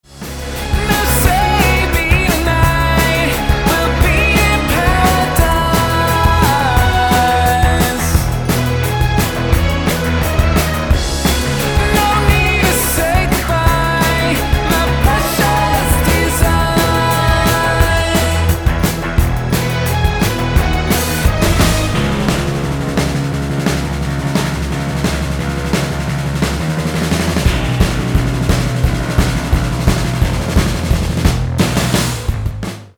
• Качество: 320, Stereo
мужской вокал
громкие
Alternative Rock
indie rock